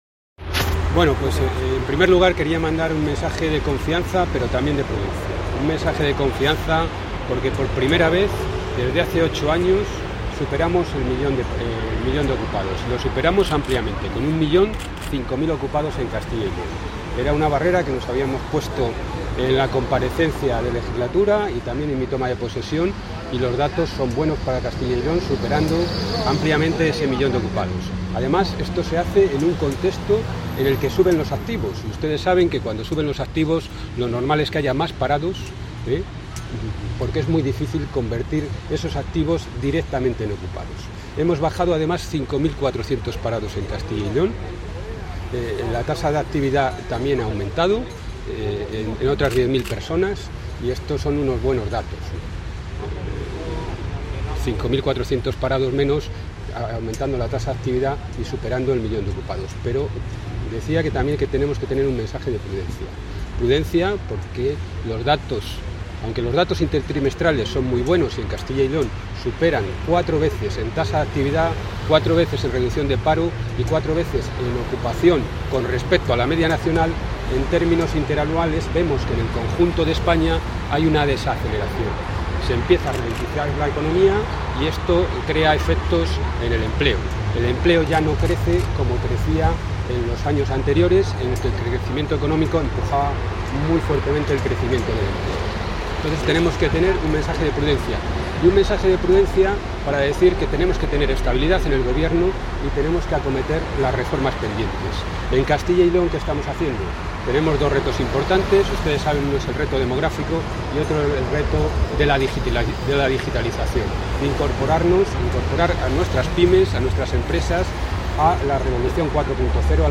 El consejero de Empleo e Industria, Germán Barrios, valora los datos de la Encuesta de Población Activa (EPA) correspondientes al tercer trimestre.